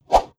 Close Combat Swing Sound 62.wav